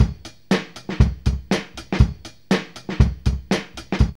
HEAVYNESS120.wav